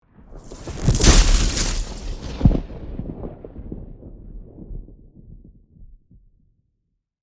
Electrifying-lightning-strike.mp3